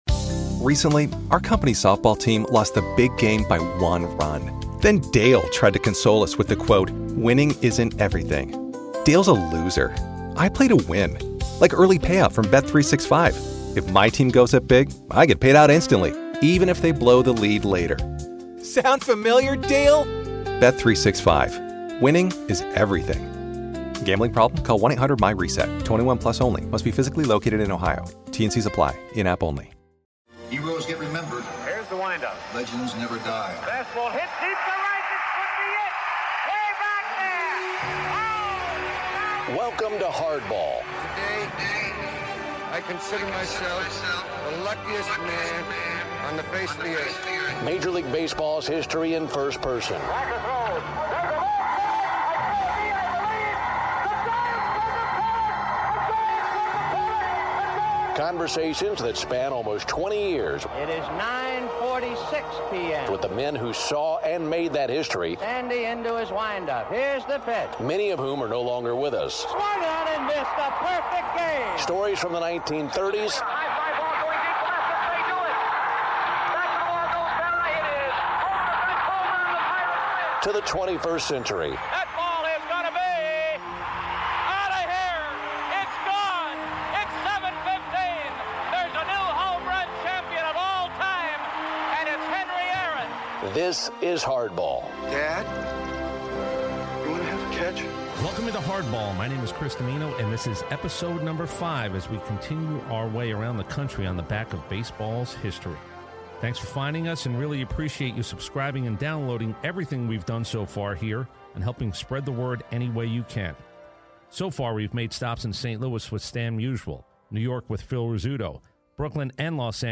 Today's Episode of HARDBALL is my conversation with Hall of Famer Carlton Fisk.